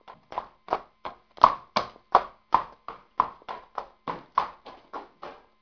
جلوه های صوتی
دانلود صدای حیوانات جنگلی 5 از ساعد نیوز با لینک مستقیم و کیفیت بالا